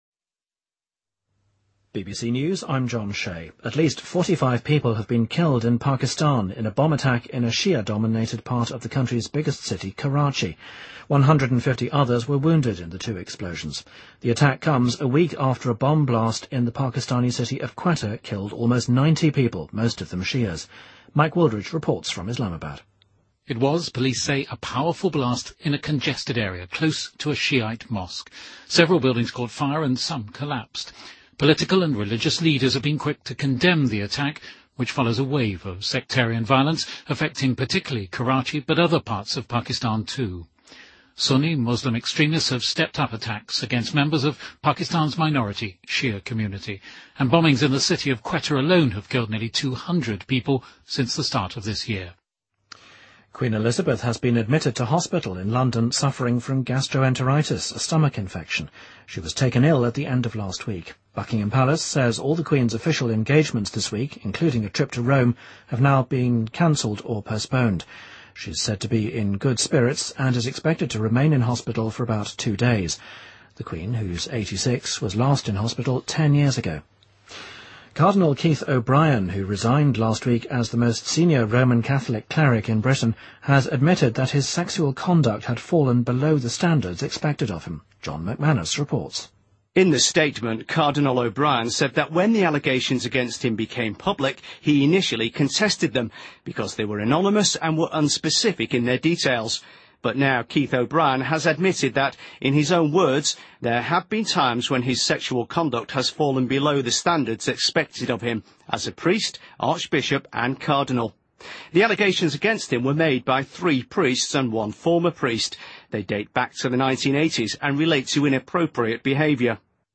BBC news,2013-03-04